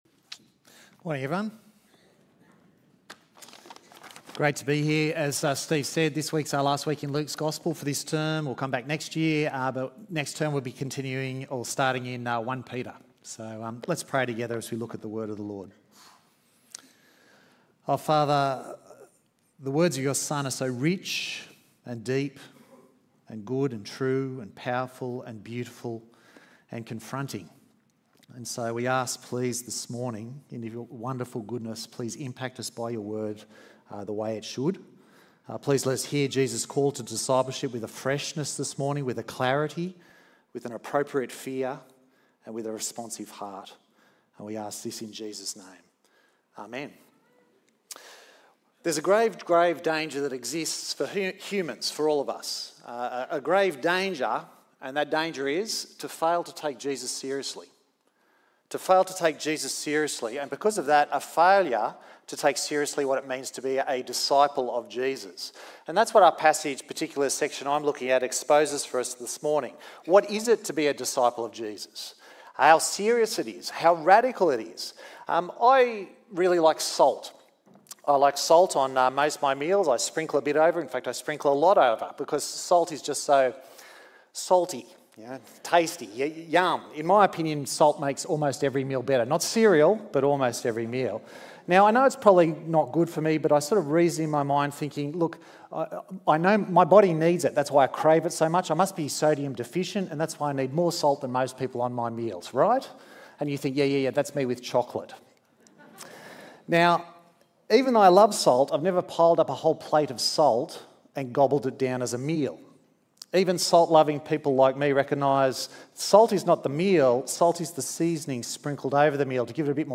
EV Church Sermons